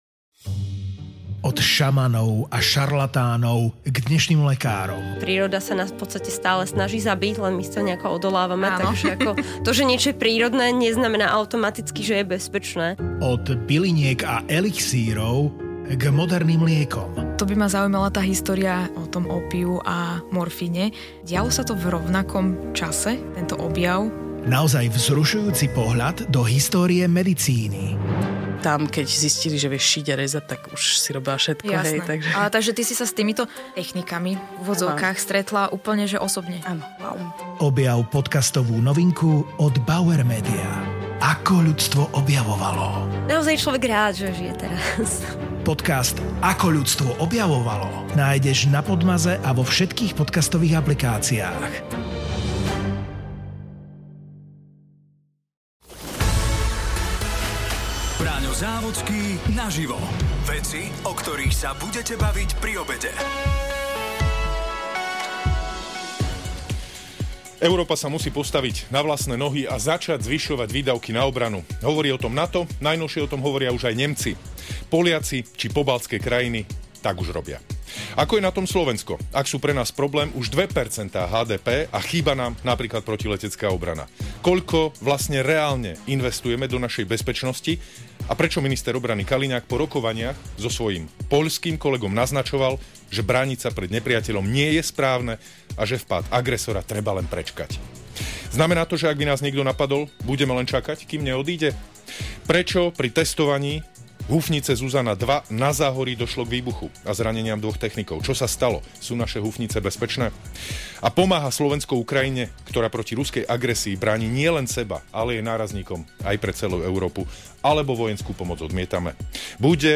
Jasné otázky – priame odpovede. Braňo Závodský a jeho hosť každý pracovný deň 10 minút po 12-tej naživo v Rádiu Expres na aktuálnu tému o veciach, o ktorých sa budete baviť pri obede.